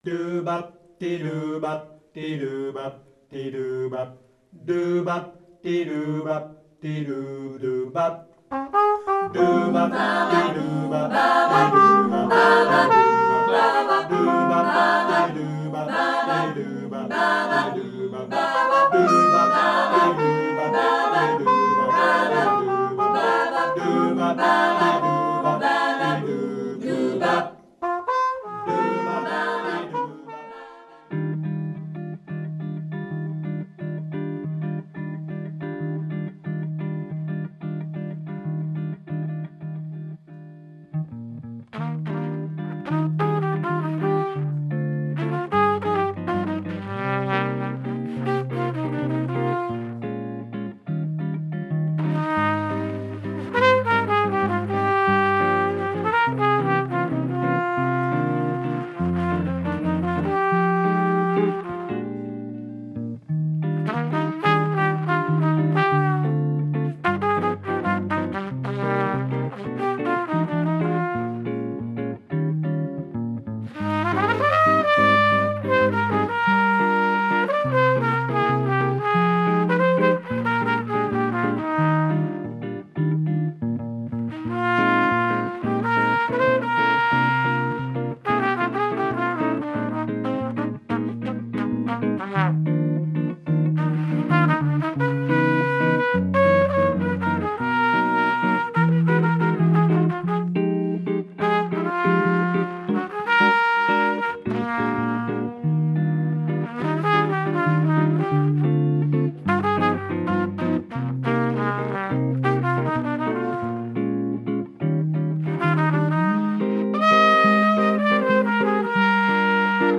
Piano
Contrebasse